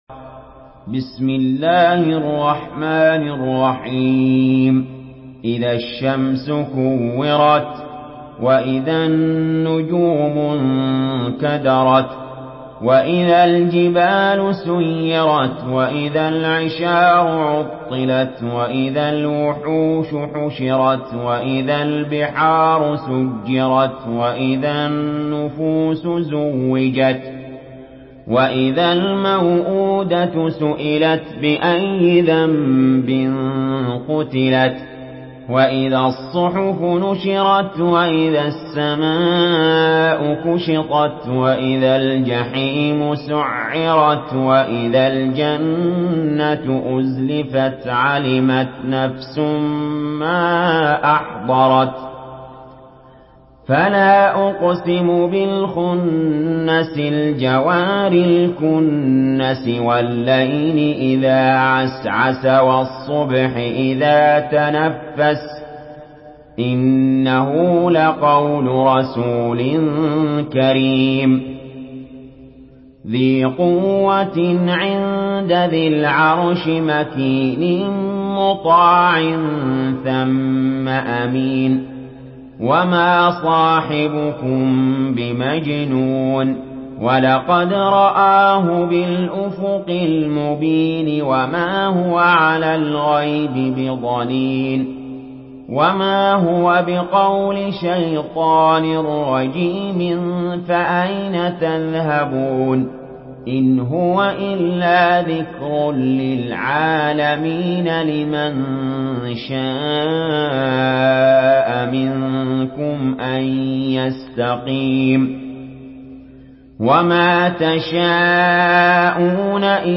Surah التكوير MP3 by علي جابر in حفص عن عاصم narration.
مرتل